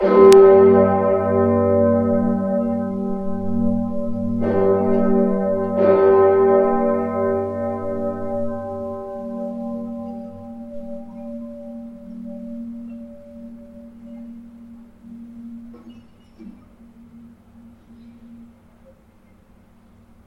Einige weitere Hörbeispiele zu verschiedenen Glocken:
Christus-Glocke Freiburg [158 KB]
christusglocke-freiburg.mp3